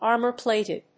アクセントármor‐pláted